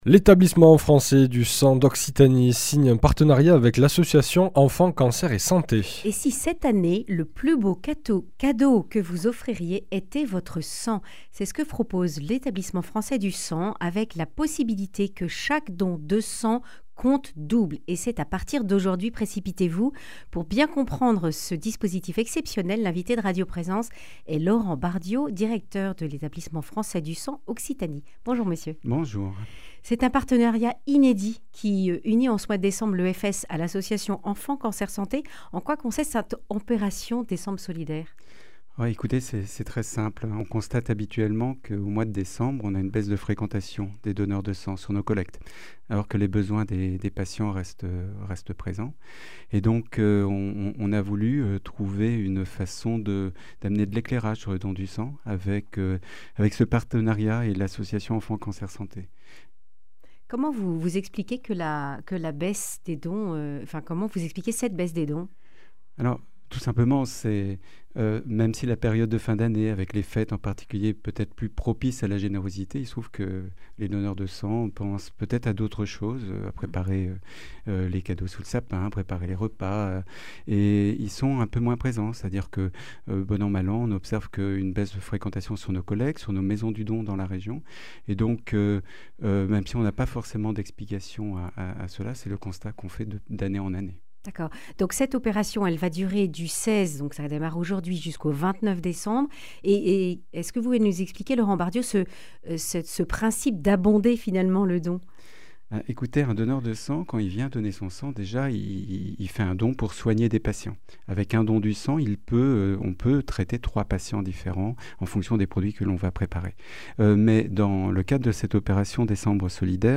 Accueil \ Emissions \ Information \ Régionale \ Le grand entretien \ Donner son sang avant le 29 décembre compte double !